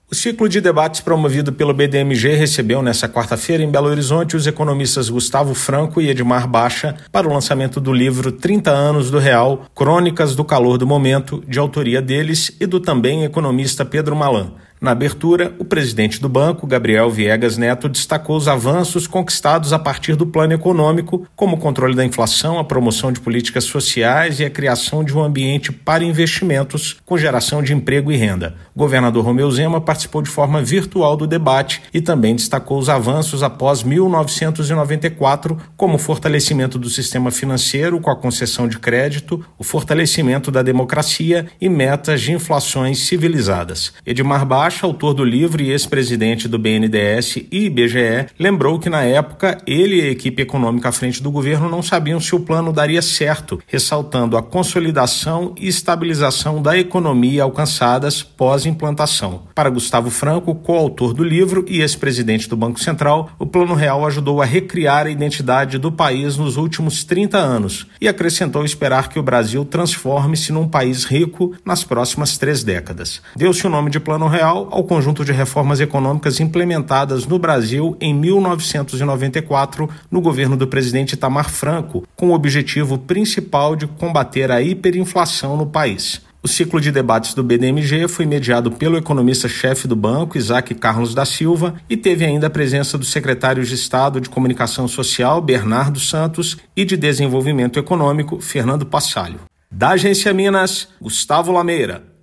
Economistas Gustavo Franco e Edmar Bacha discutiram o legado do Plano Real e perspectivas da economia no lançamento do livro em Belo Horizonte. Ouça matéria de rádio.